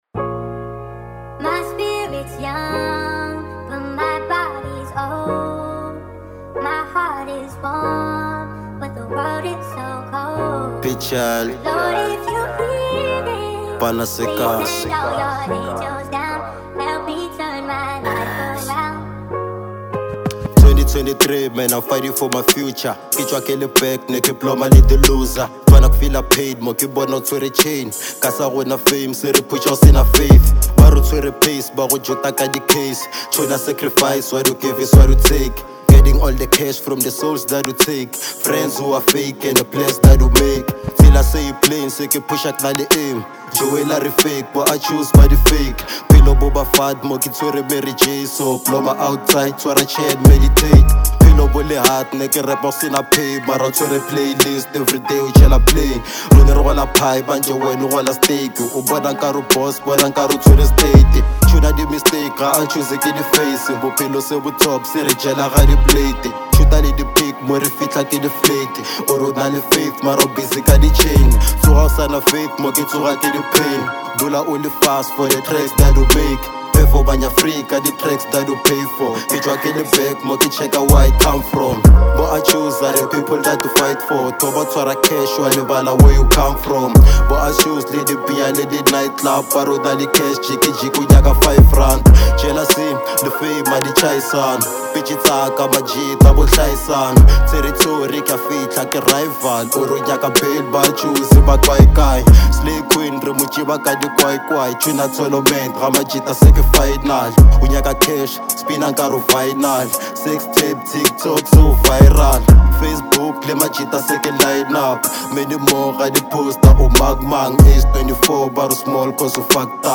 04:27 Genre : Hip Hop Size